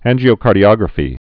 (ănjē-ō-kärdē-ŏgrə-fē)